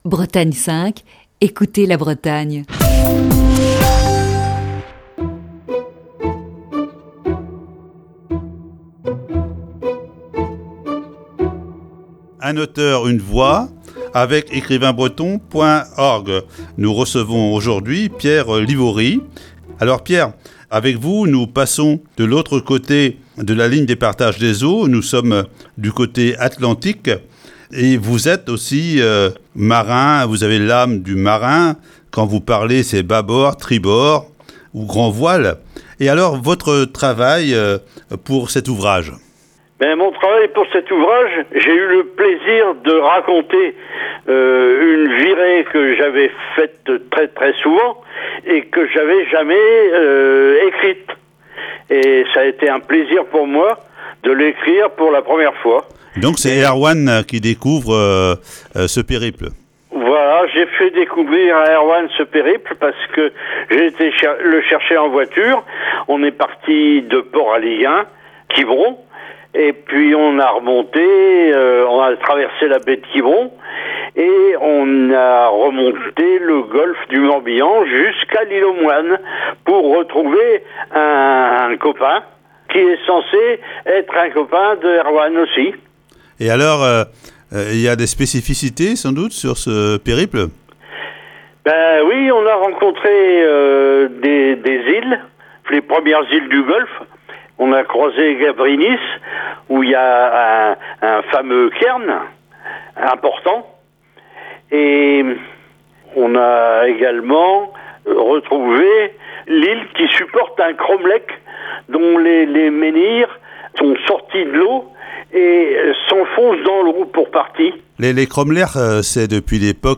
Chronique du 31 mars 2021.